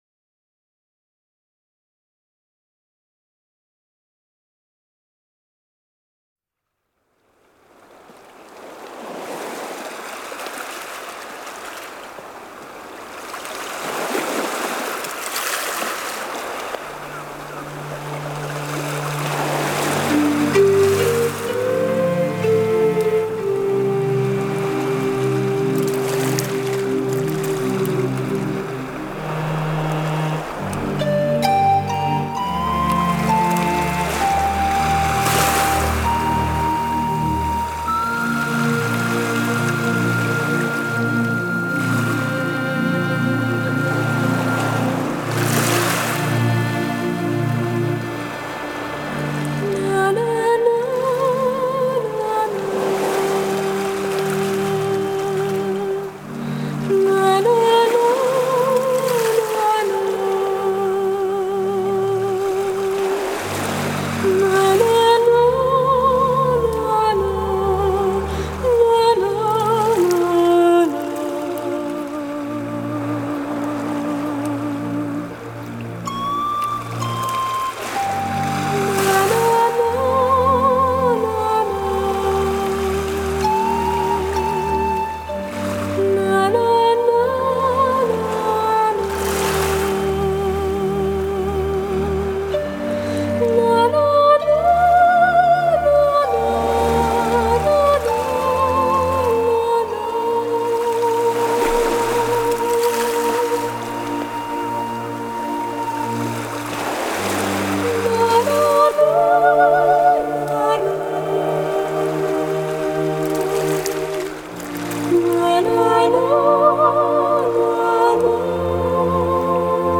德国BELL真空管录制,声音密度极高，声效直接向LP挑战！